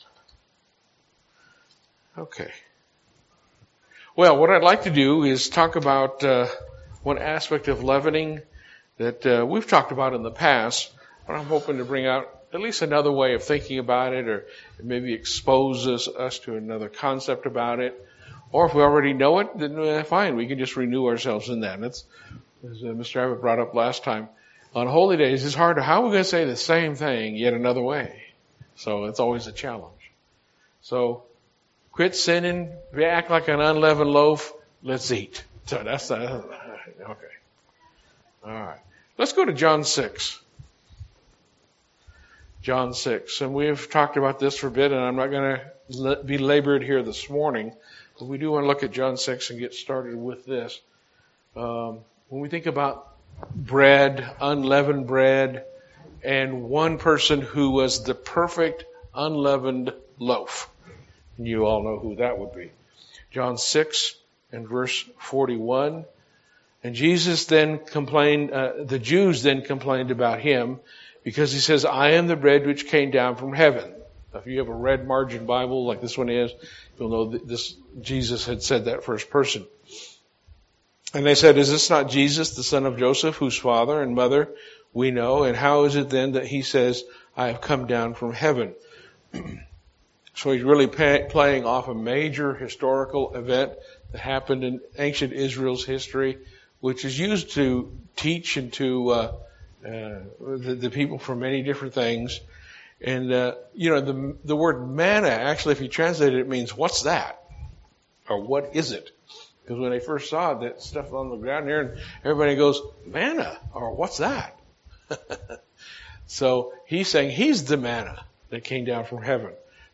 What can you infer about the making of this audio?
Given in Lubbock, TX